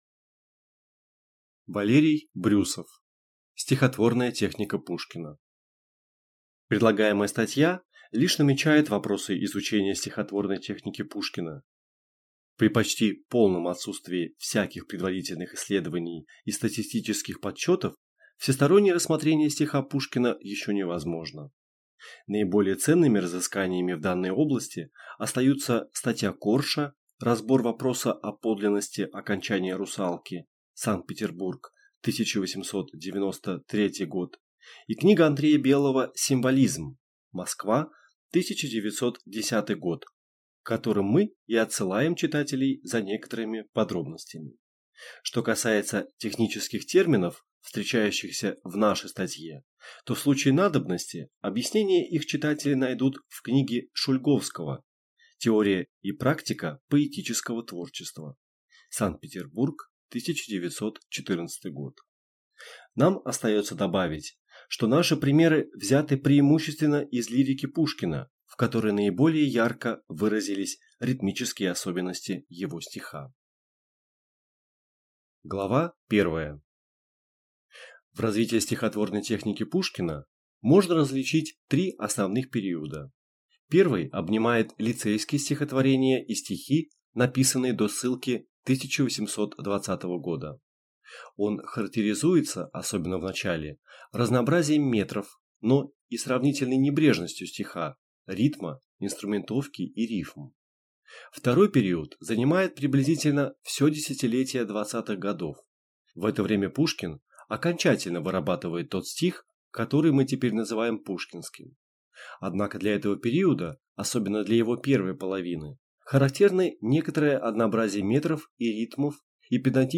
Аудиокнига Стихотворная техника Пушкина | Библиотека аудиокниг